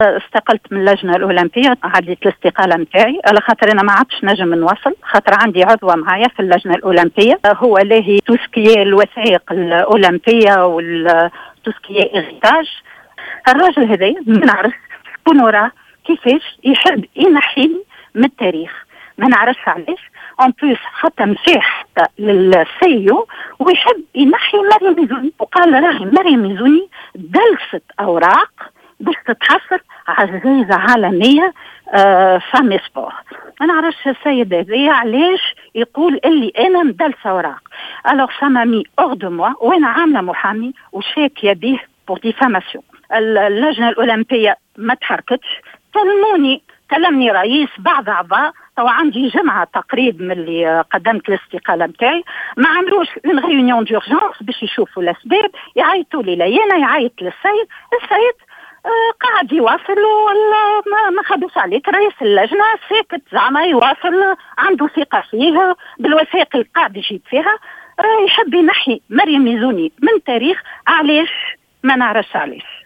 في تصريح لجوهرة اف ام